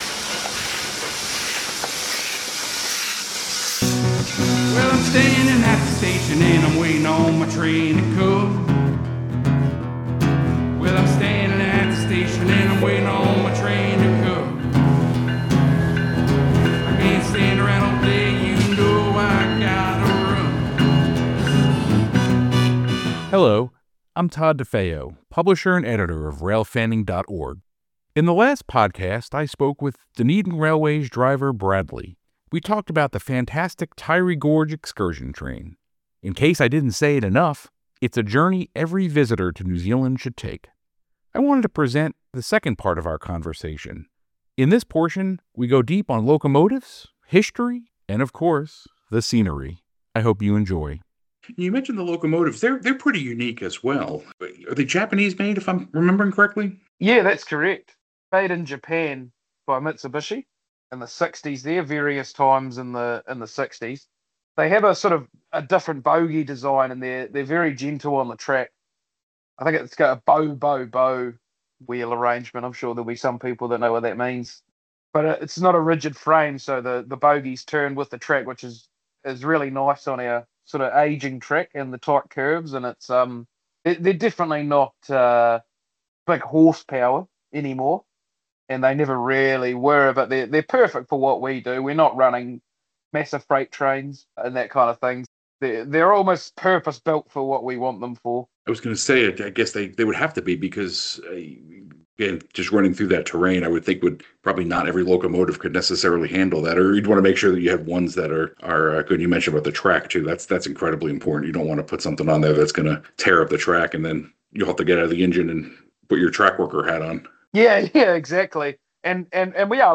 Show Notes This conversation snippet has been slightly edited.